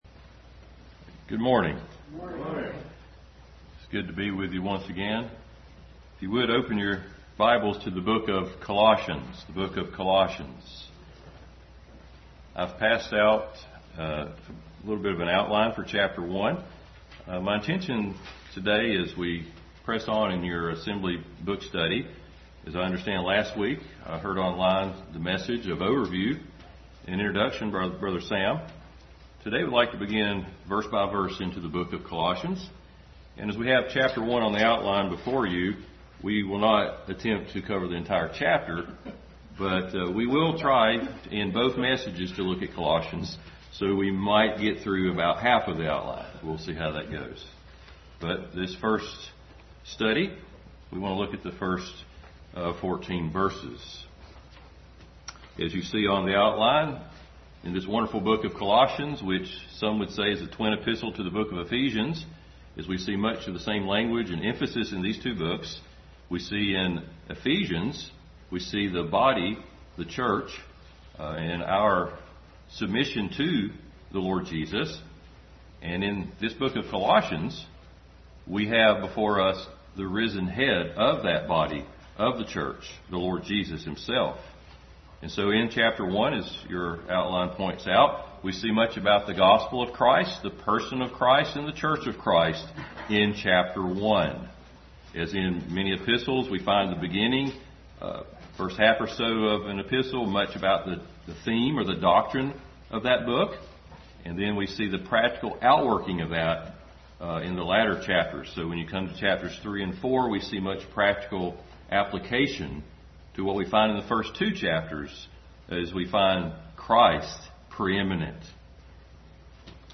Adult Sunday School study in Colossians.